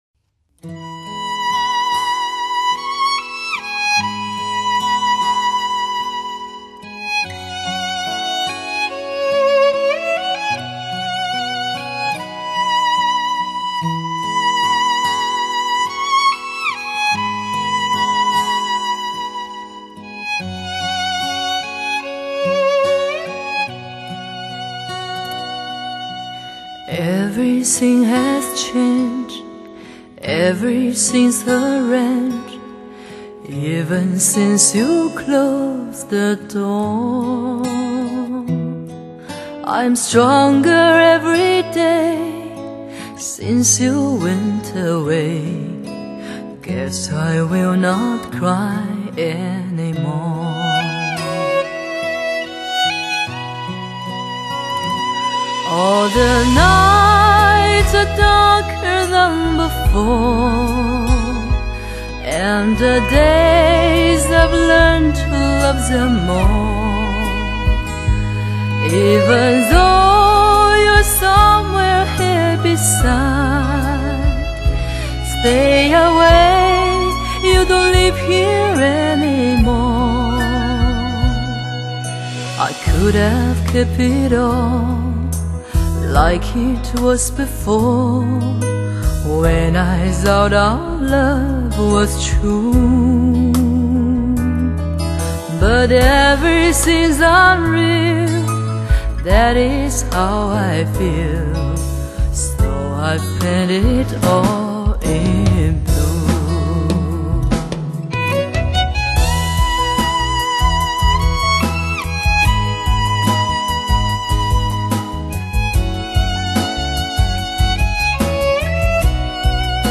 这是一个池塘生春草般自然的声音，有如梅子黄时雨，偷偷打湿你的窗棂，有如一城风絮，悄悄萦绕着你的衣袂。
她的歌声让你清晰地看到蝴蝶翅膀背面的花纹，那是美丽和哀愁的交错孽生。
撕心裂肺的男人情歌由她唱来，凸显女声的清雅飘逸和缠绵柔情，细细咀嚼，满口余香。